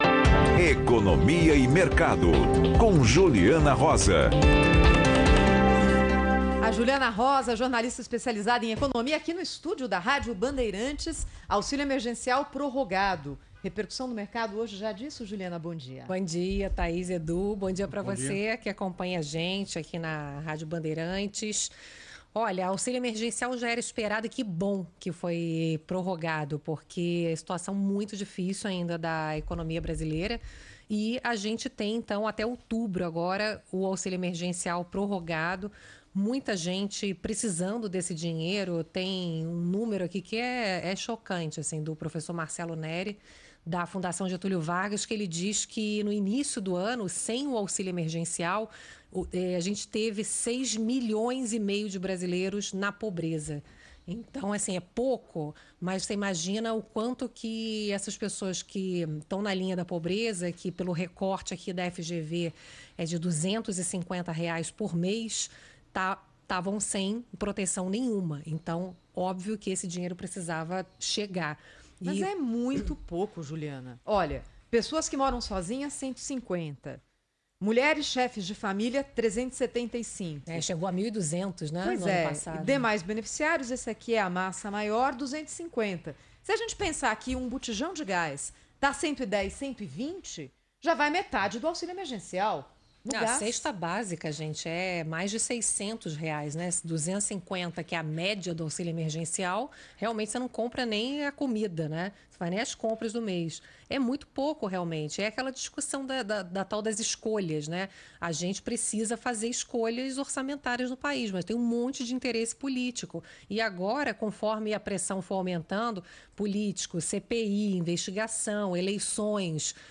• Rádio